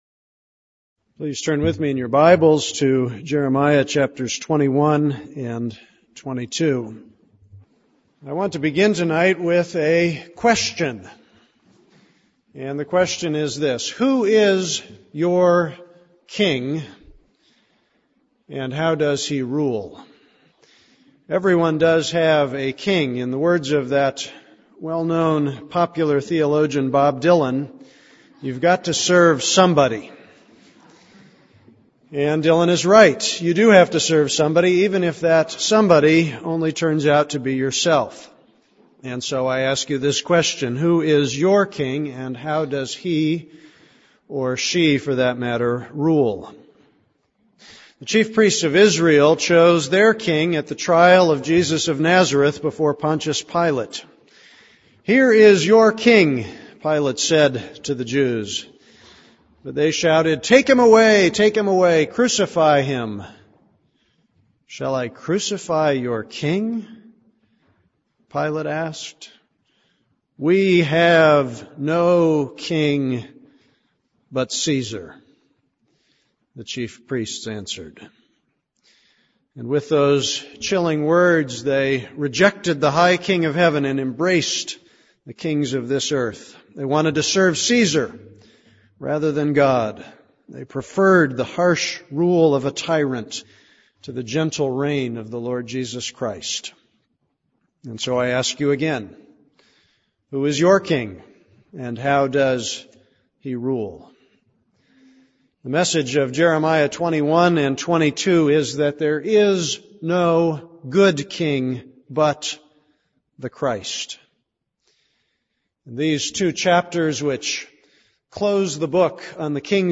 This is a sermon on Jeremiah 26:15-16.